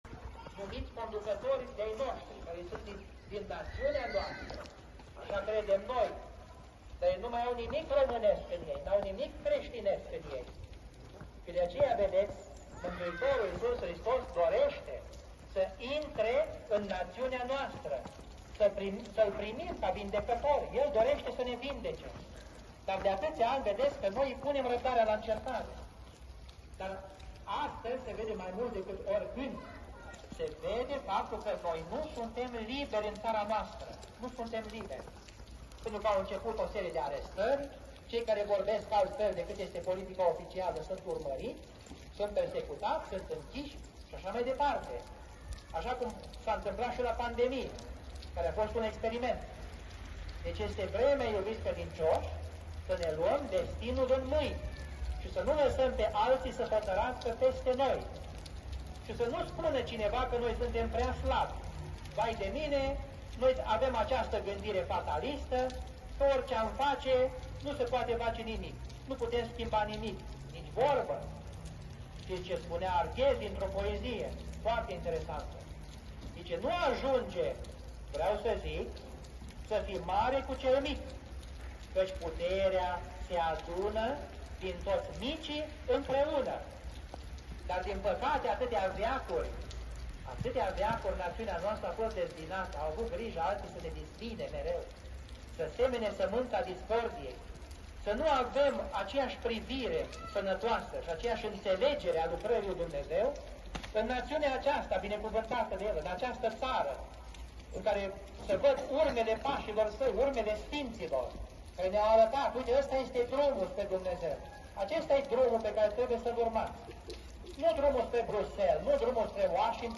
La catedrala din Vatra Dornei slujbele sunt transmise la o boxă. Asta face ca liturghia și predicile să poată fi auzite nu doar din biserică, ci și din afara ei.
Înregistrare audio cu predica de la Catedrala din Vatra Dornei. 8 decembrie 2024
Predica a fost înregistrată de o persoană care se afla în preajma Catedralei din Vatra Dornei.